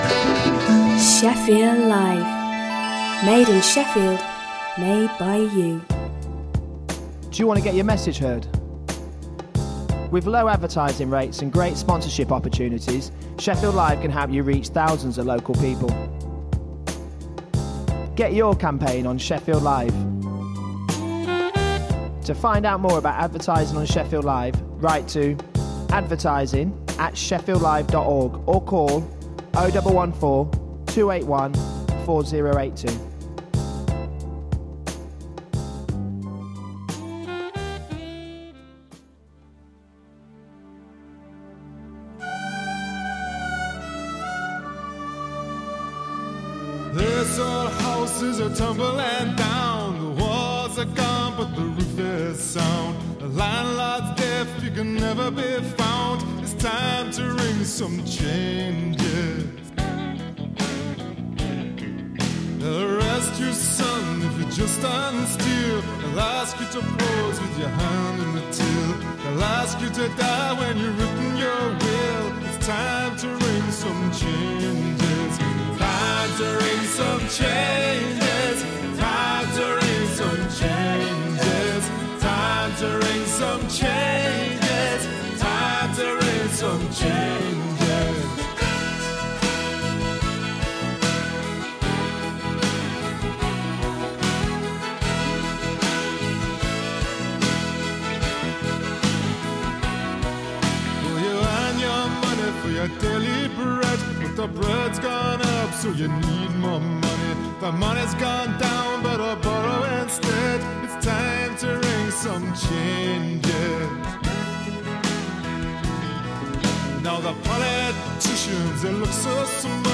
The very best new upfront music ahead of release dates & classic old tunes ranging round dub, chill, breaks, jungle, dnb, techno & anything else